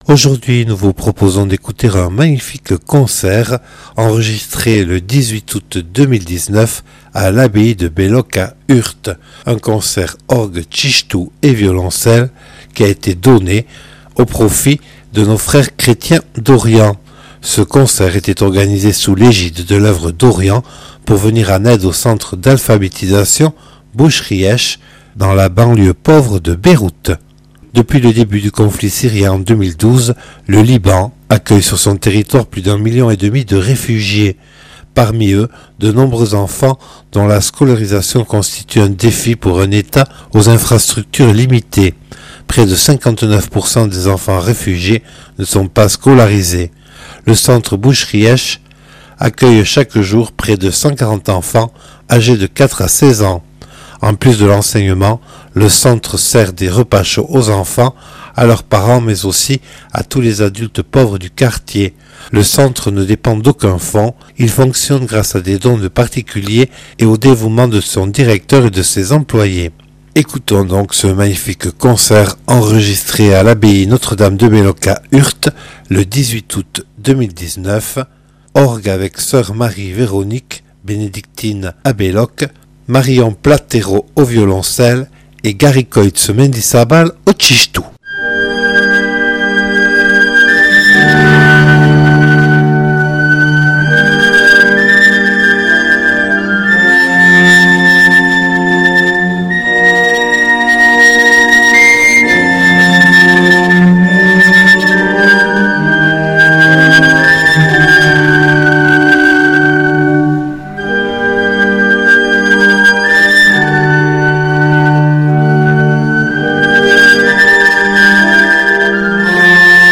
Extraits du concert "Orgue-Txistu-Violoncelle" du 18 août 2019 à l'abbaye Notre Dame de Belloc